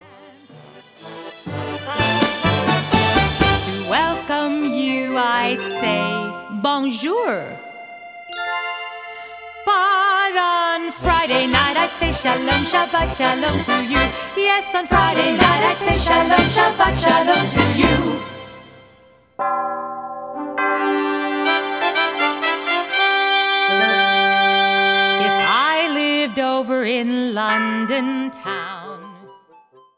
Popular children's performer and recording artist
a delightfully fun recording of 12 easy to learn songs